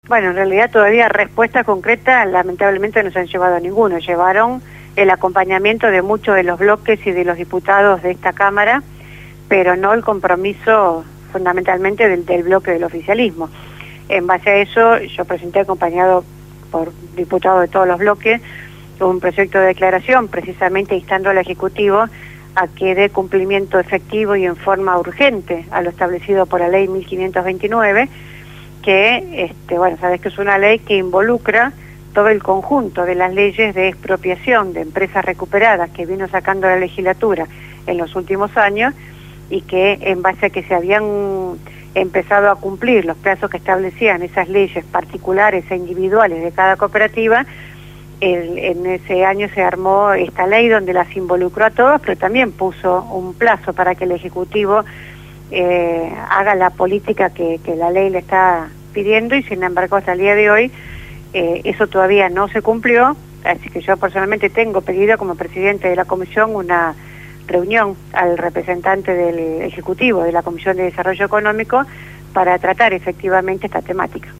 Los compañeros de «Fabricando Alternativas» profundizaron en su investigación y entrevistaron a la legislador de Proyecto Sur Delia Bisutti. Consultada sobre porque no se aplica la Ley 1529 de expropiación definitiva en pos de los trabajadores de las recuperadas, ésto declaró: